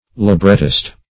Librettist \Li*bret"tist\ (l[i^]*br[e^]t"t[i^]st), n.